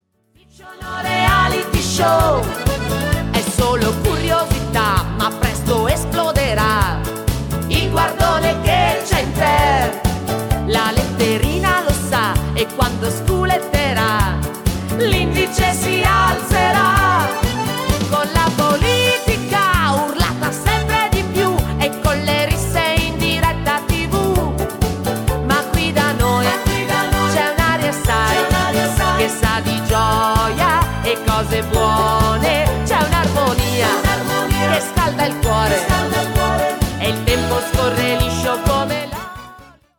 TARANTELLA  (3.56)